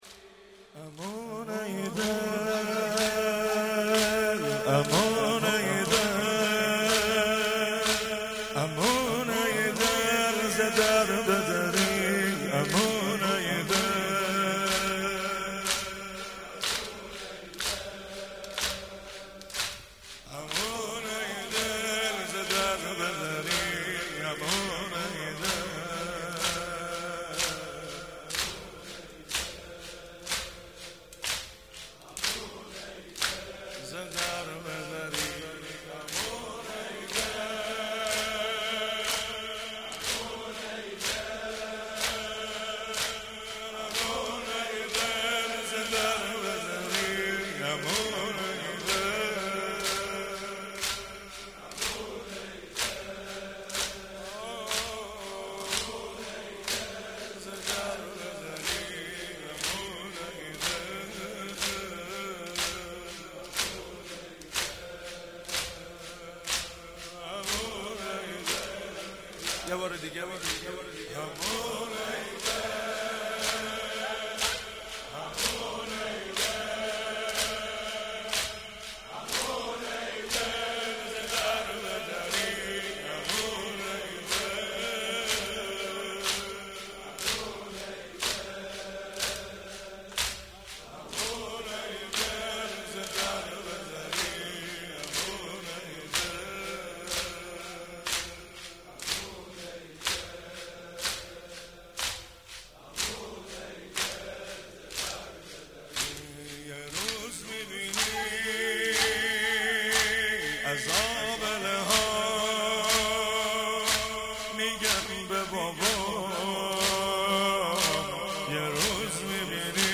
اول صفر 97 - زمینه - امون ای دل